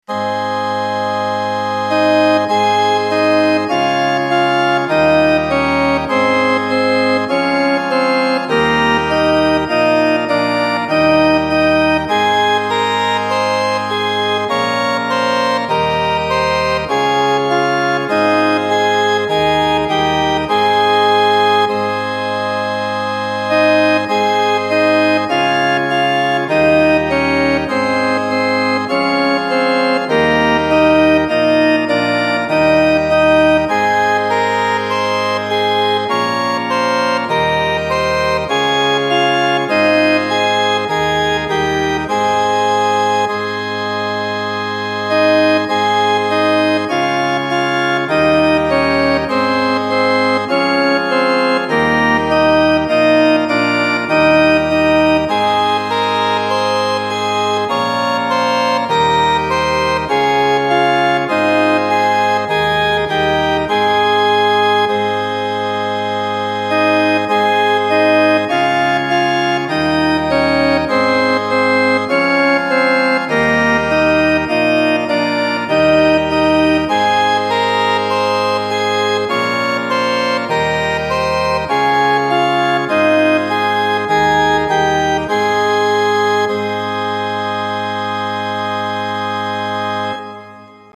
More fake organ from BIAB: